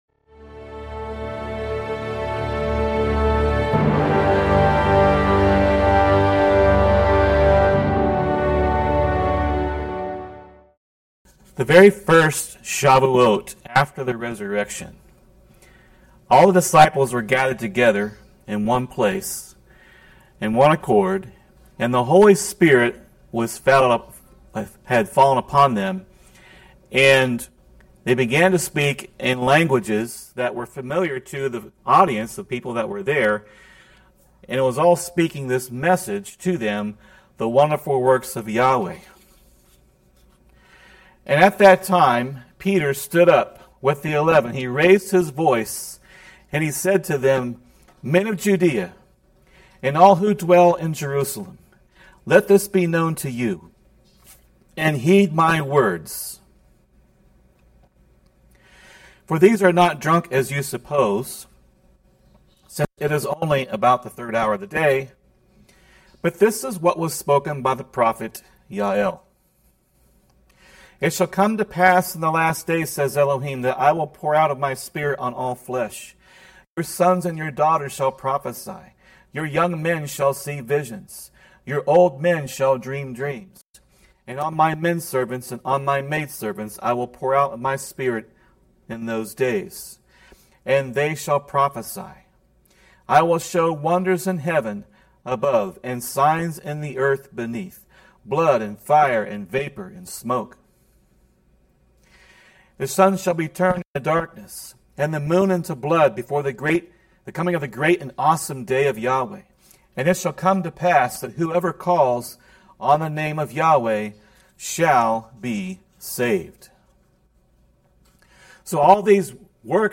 Video Transcript This is a direct transcript of a teaching that was presented via video.